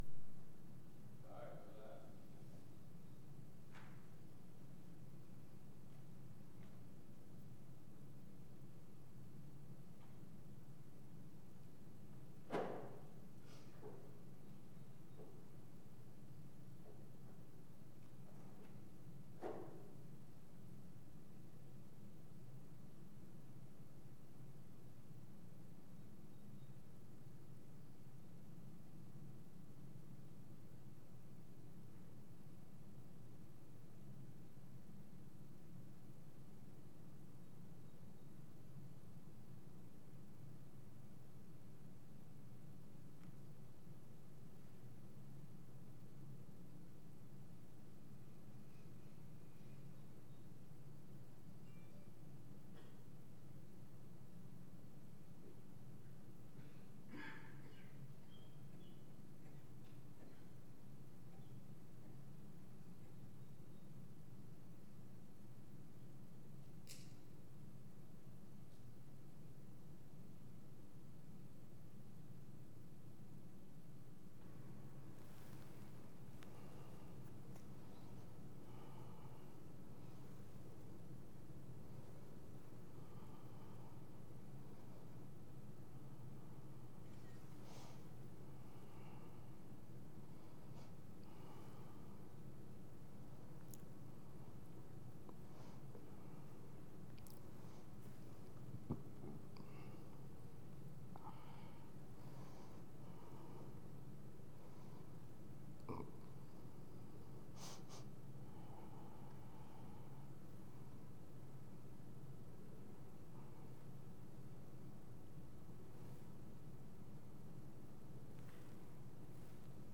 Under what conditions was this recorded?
Romans 12:9-16 Service Type: Morning Bible Text